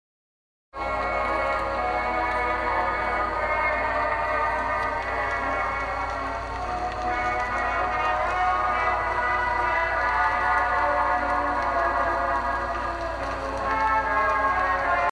Error sound effects free download